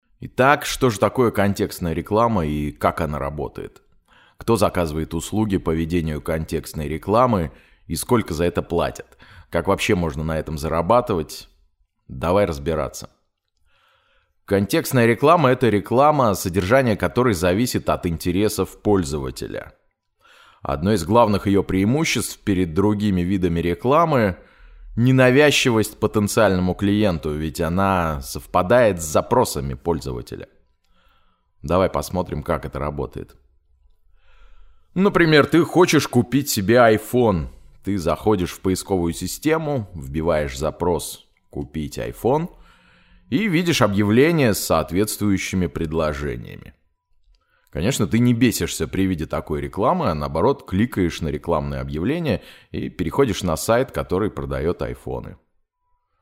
Некоторое время назад мне начали заказывать женские романы эротического содержания и я обнаружил, что мой голос имеет особенный успех у женщин :) По манере стараюсь держаться между дикторским и актёрским стилем, если нет других указаний от заказчика.
Marshall Electronics MXL 909 M-Audio Firiwire Solo